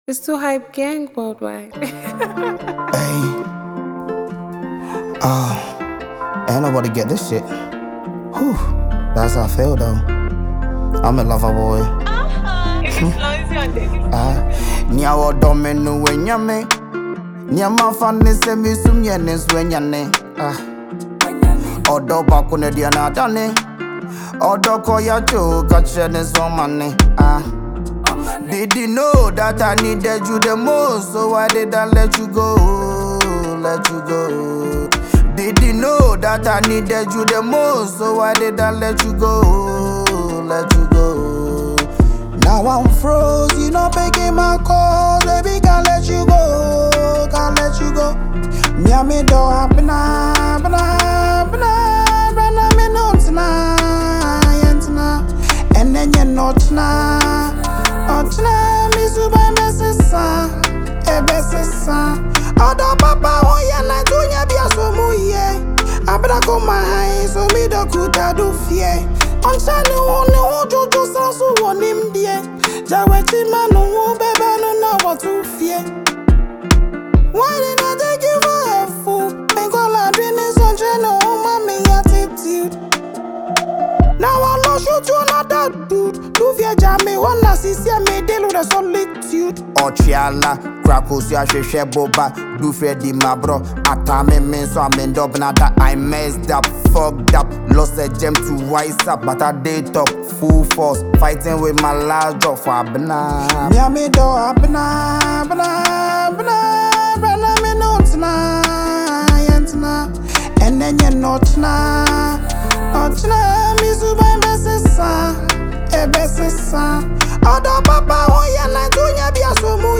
sweet Afro-fusion sound
Genre: Afrobeat / Love Song